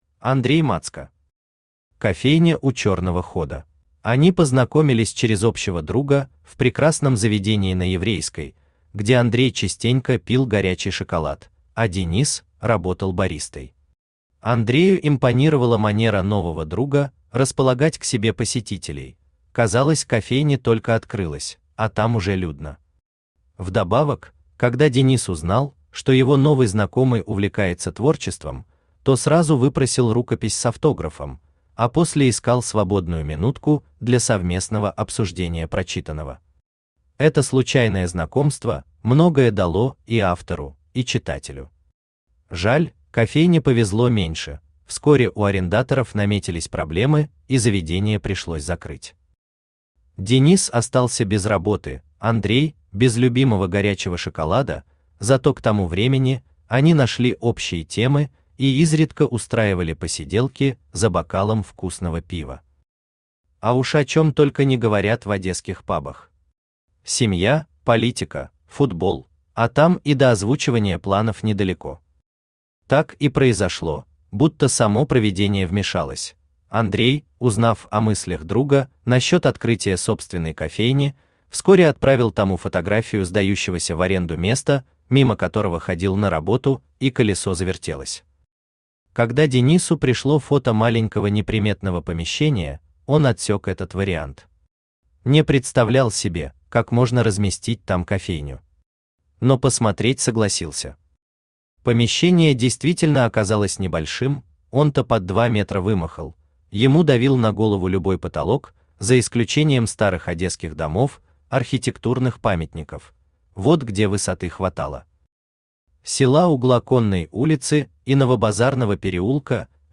Аудиокнига Кофейня у черного хода | Библиотека аудиокниг
Aудиокнига Кофейня у черного хода Автор Андрей Мацко Читает аудиокнигу Авточтец ЛитРес.